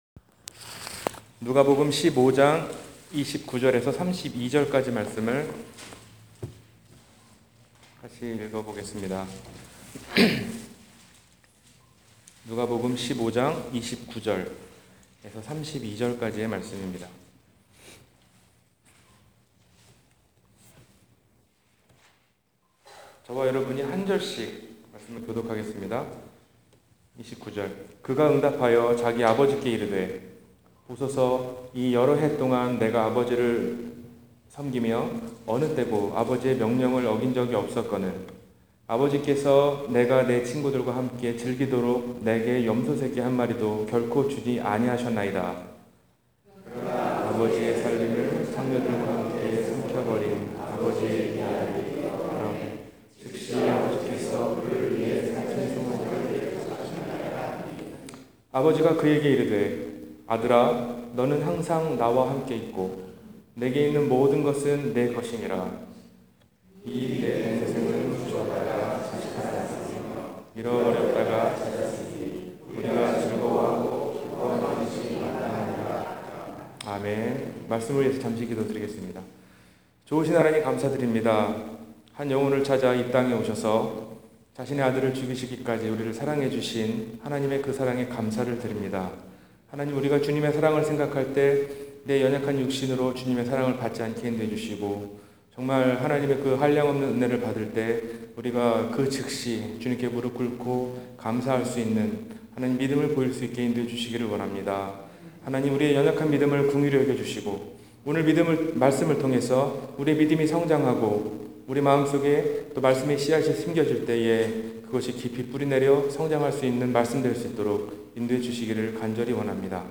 잃어버린 것을 찾으시는 하나님 – 주일설교